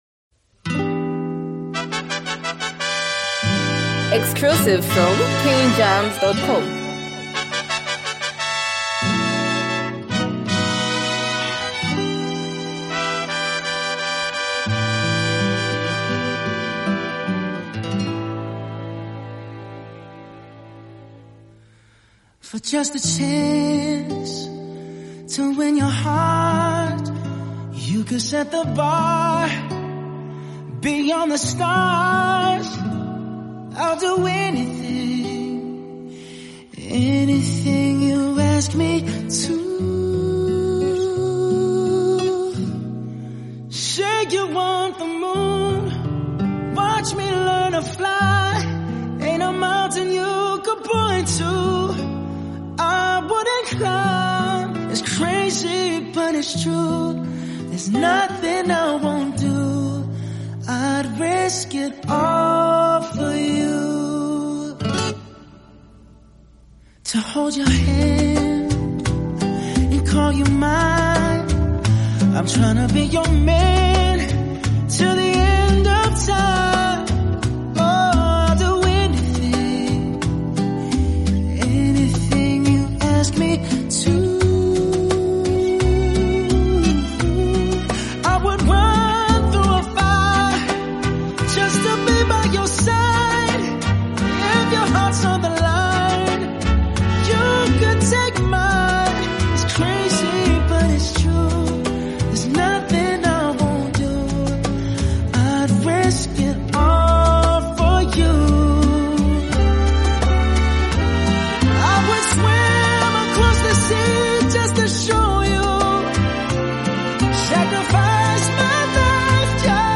an emotional and heartfelt song